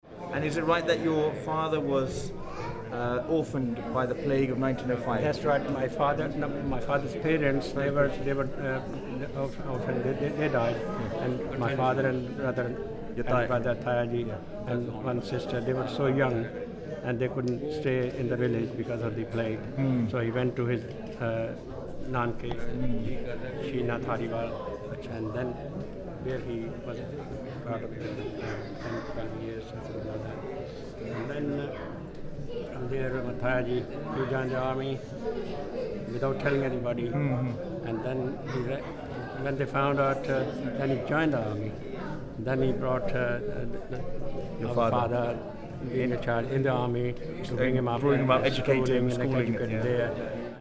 Location: Brunei Gallery, London